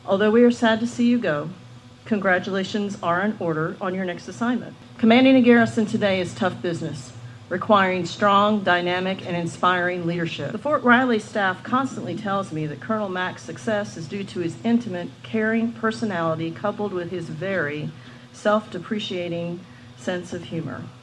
A change of command ceremony for the two colonels took place Tuesday.